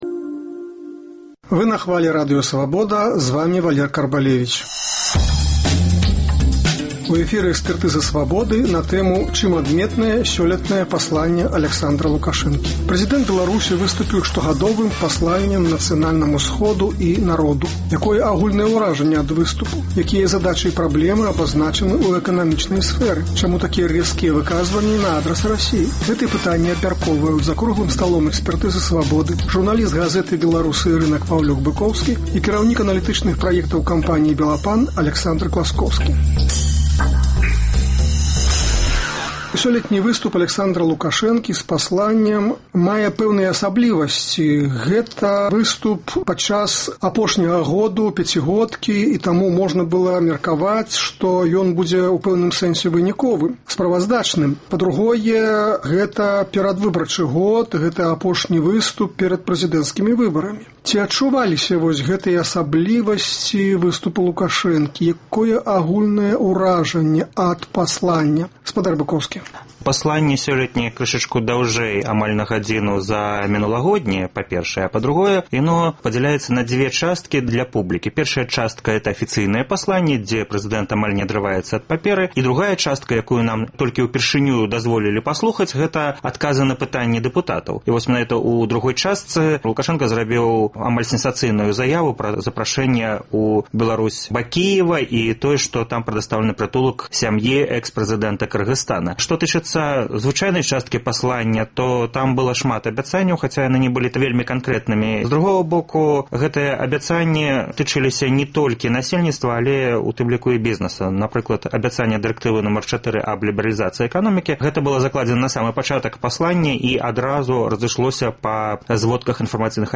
Якія задачы і праблемы абазначаны ў эканамічнай сфэры? Чаму такія рэзкія выказваньні на адрас Расеі? Гэтыя пытаньні абмяркоўваюць за круглым сталом "Экспэртызы "Свабоды”